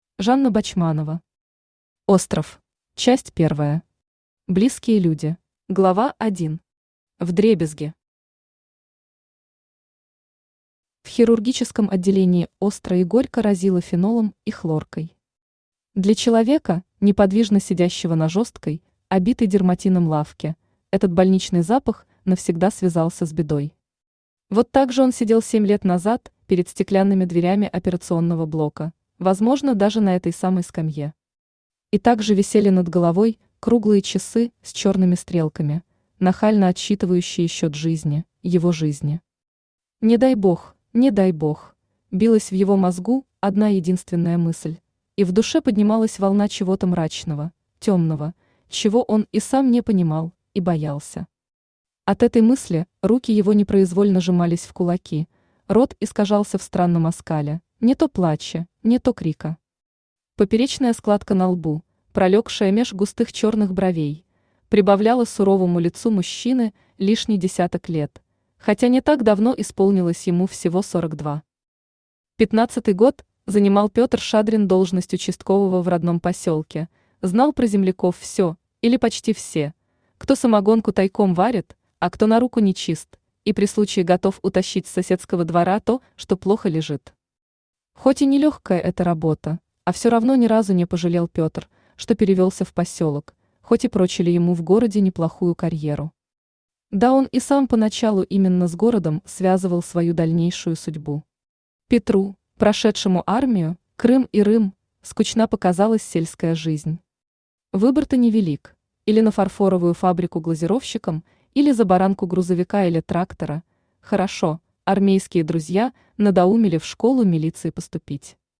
Аудиокнига Остров | Библиотека аудиокниг
Aудиокнига Остров Автор Жанна Бочманова Читает аудиокнигу Авточтец ЛитРес.